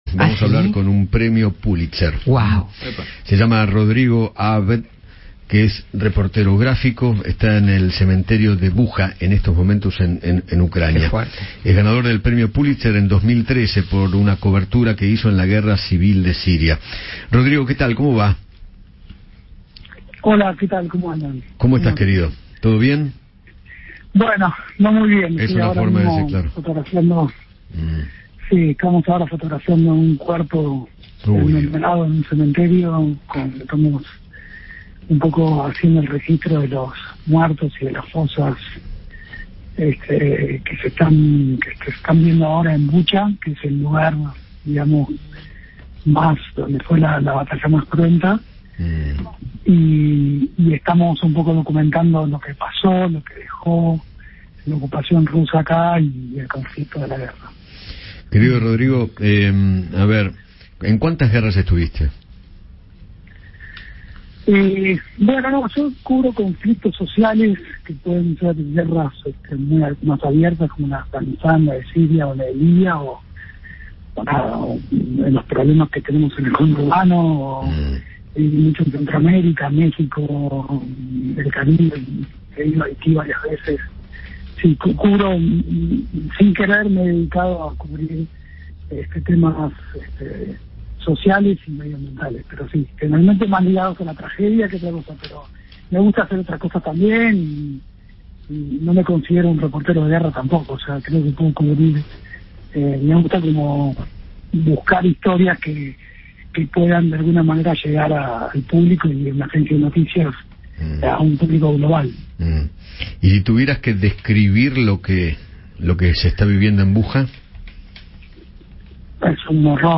El fotoperiodista argentino Rodrigo Abd, quien ganó el premio Pullitzer en 2013, conversó con Eduardo Feinmann sobre su experiencia cubriendo conflictos bélicos y se refirió a la cobertura en medio de la guerra de Ucrania.